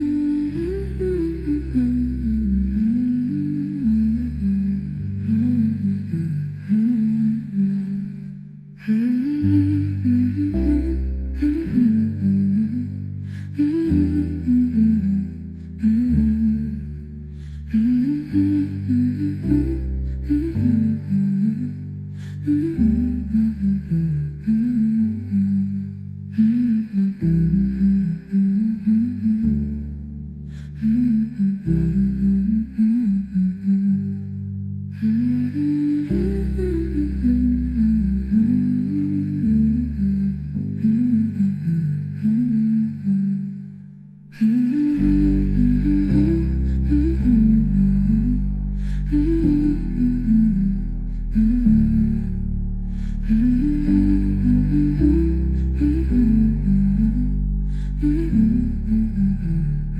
Magic Lighters ASMR | PT sound effects free download